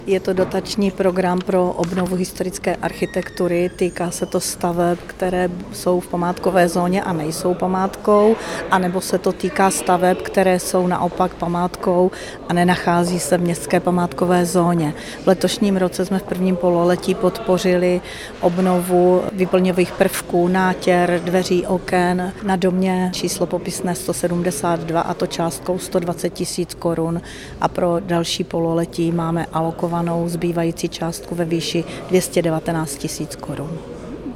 Místostarostka Bc. Pavlína Jagošová: